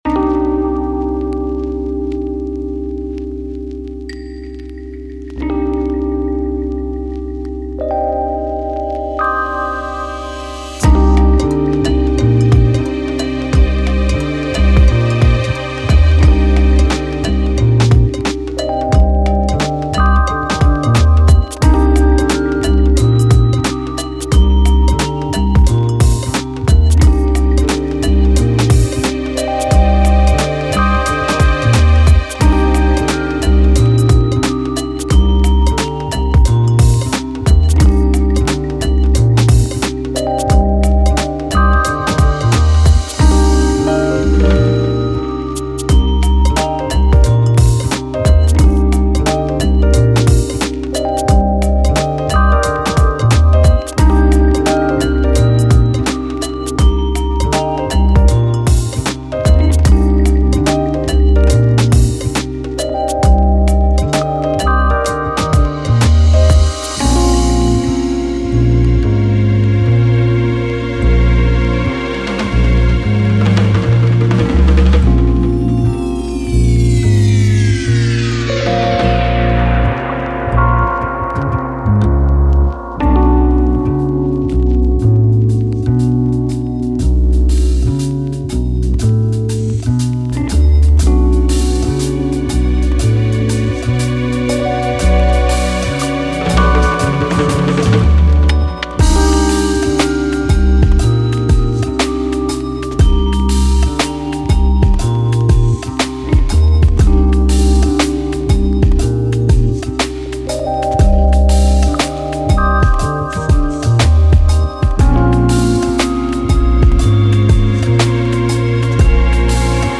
I like the strong bass